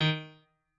pianoadrib1_34.ogg